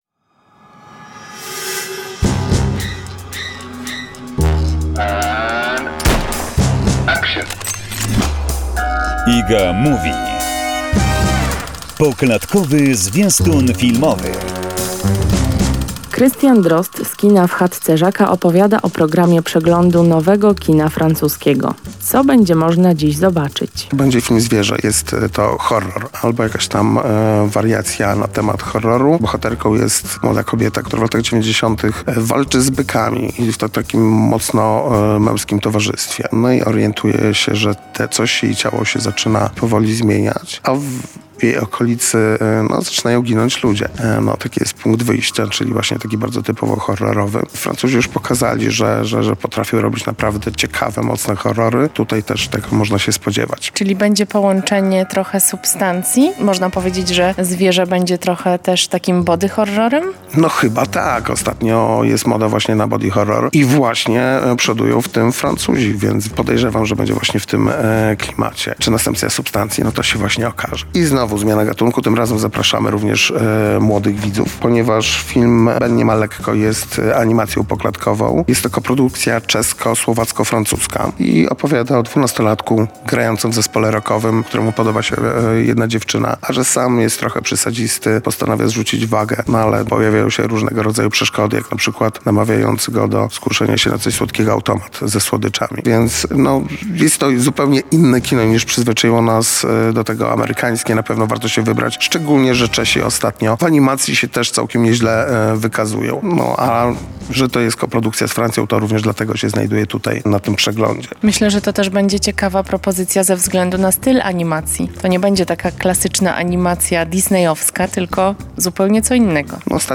zaprasza do wysłuchania rozmowy